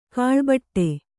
♪ kāḷbaṭṭe